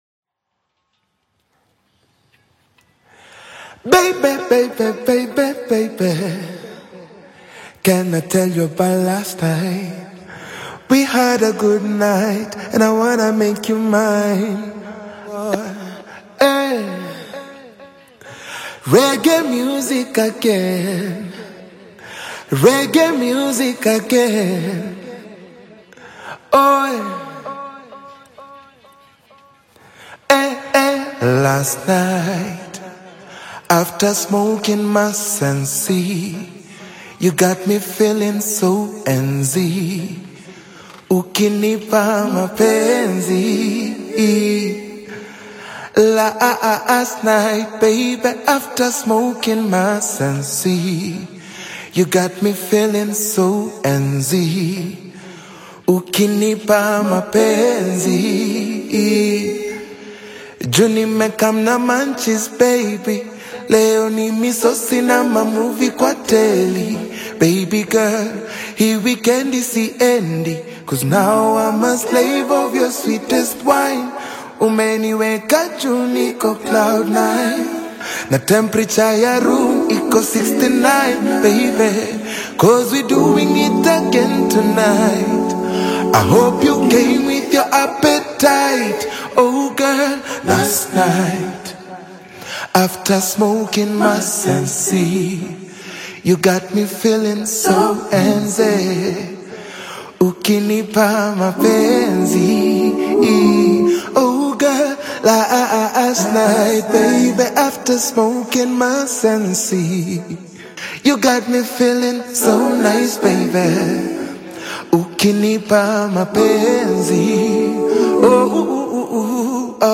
signature soulful vocals